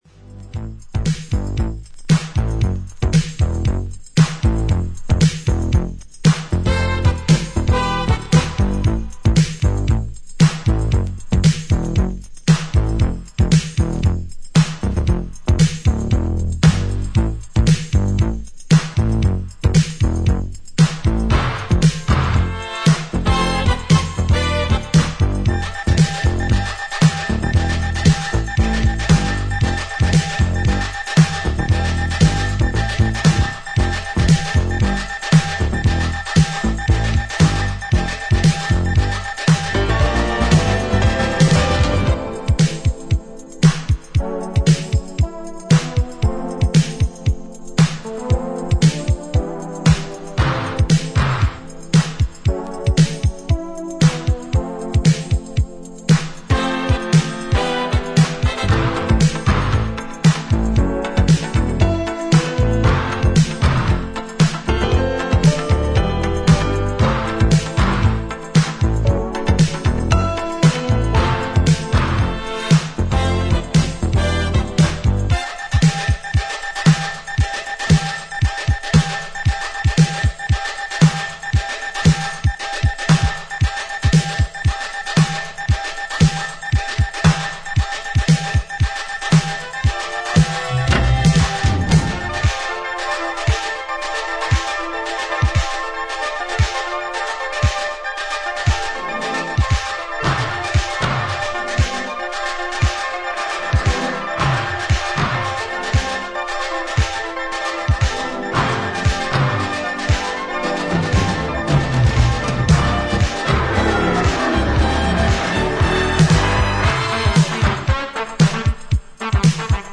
しっとりとした質感のサウンドとアンニュイなコード感
インスト・ヴァージョン
(Vocal)